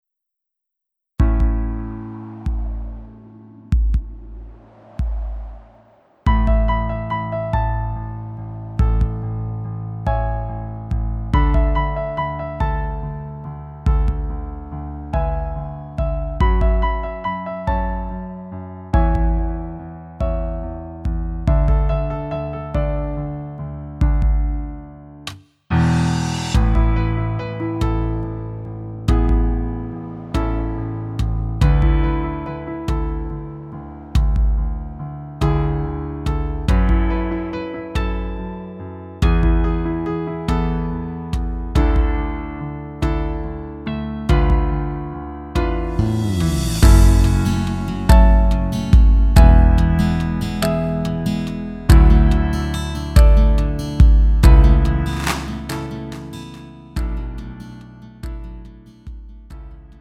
음정 원키 3:47
장르 가요 구분